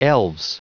Prononciation du mot elves en anglais (fichier audio)
Prononciation du mot : elves